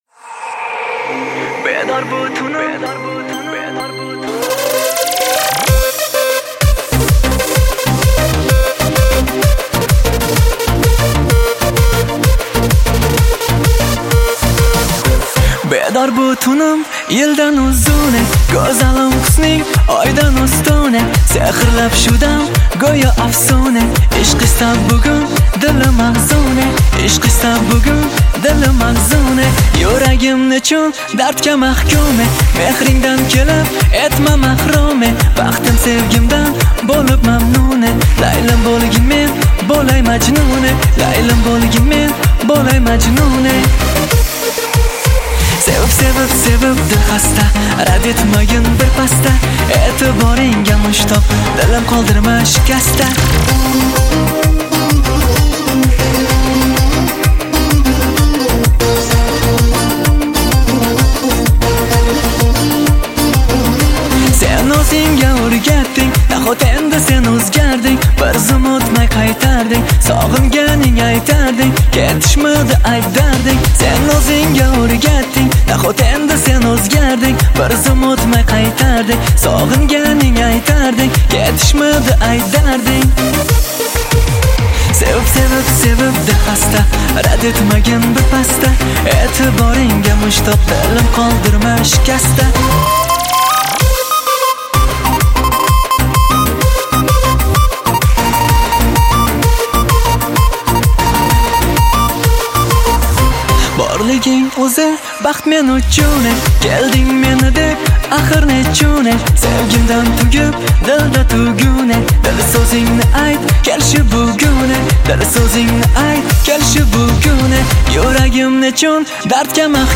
• Метки: Remix